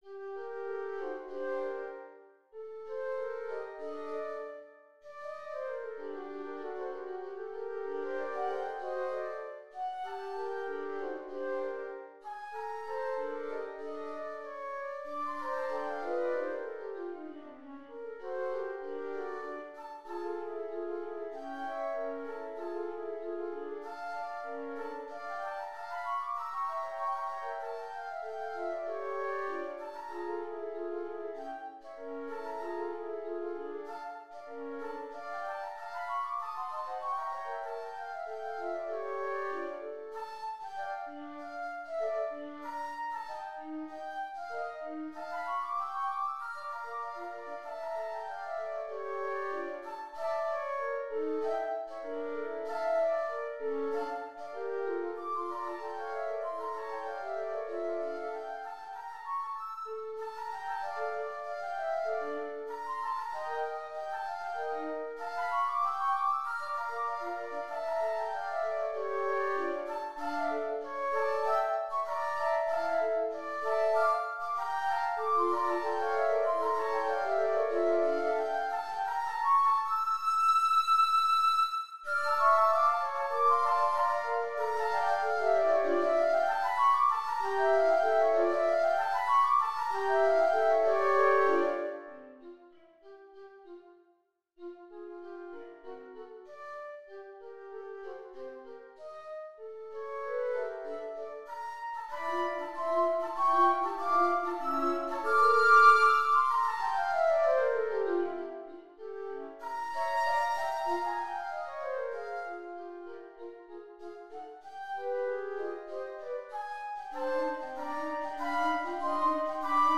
Flute Trio for the Month of Beginnings (MIDI)